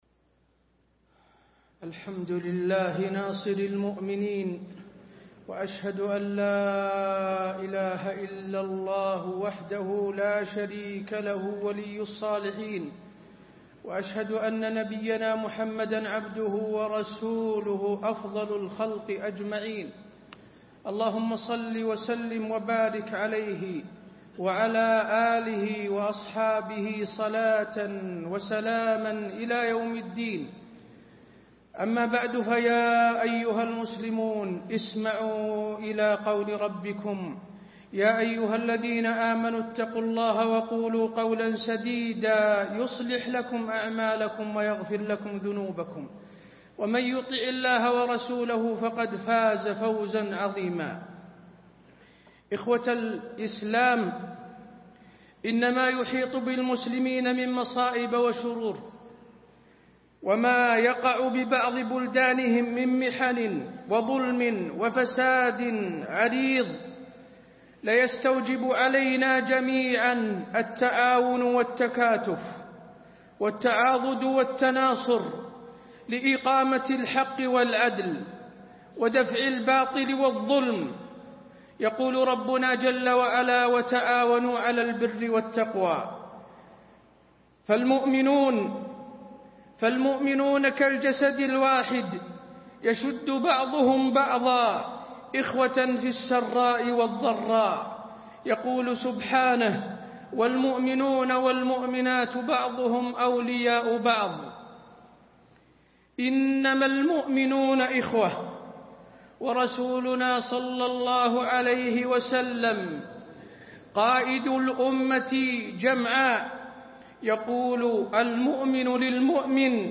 تاريخ النشر ٥ شعبان ١٤٣٤ هـ المكان: المسجد النبوي الشيخ: فضيلة الشيخ د. حسين بن عبدالعزيز آل الشيخ فضيلة الشيخ د. حسين بن عبدالعزيز آل الشيخ سوريا والجسد الواحد